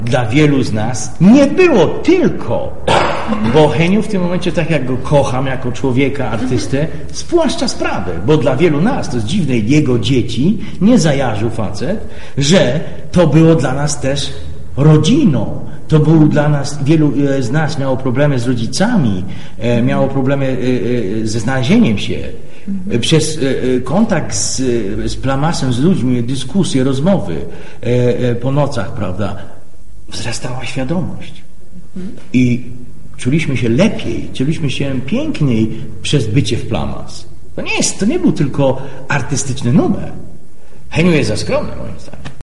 Tak 3 lata temu podczas jubileuszu grupy artystów plastyków Plama’s